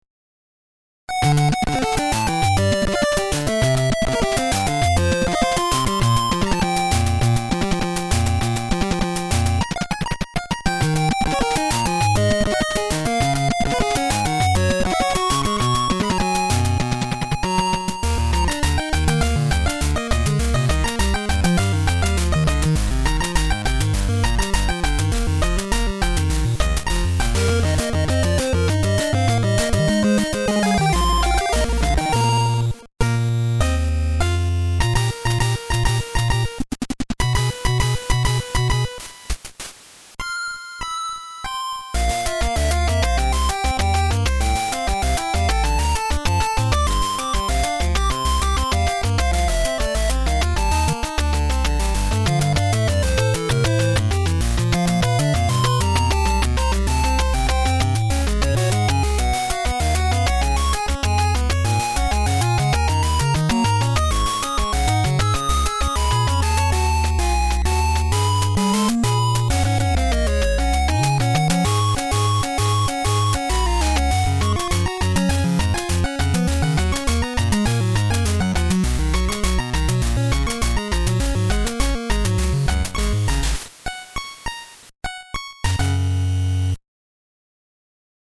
打ち込みやっつけ
ドット絵とレトロなBGMって本当に良いものですし…
FC風の音源再現も相変わらず凄いし…